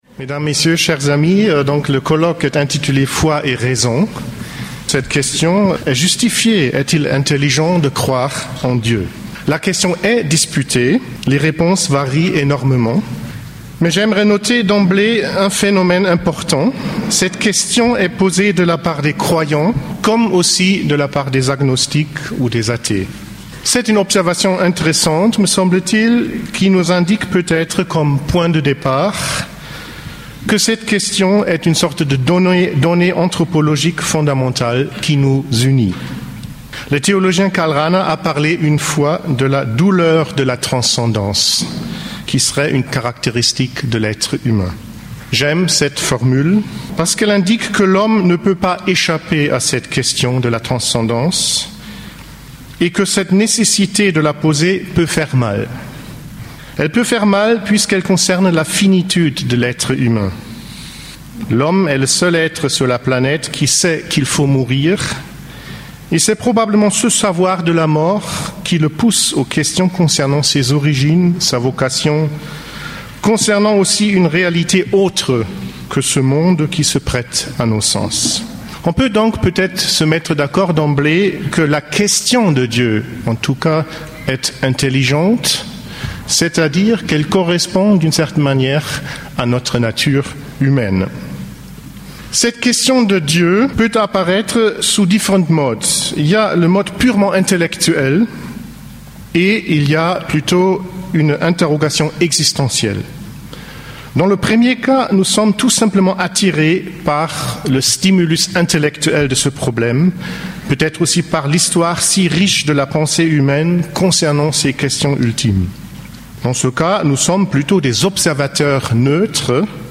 Attention, cette conf�rence a �t� donn�e lors d'un colloque de haute vol�e et peut �tre difficile pour une personne non sp�cialiste.
Enregistr� dans le cadre du Colloque de Philosophie et de Th�ologie, � l'occasion des 10 ans de fondation du Studium de Philosophie de Chartres et de l'Institut de Th�ologie des Dombes.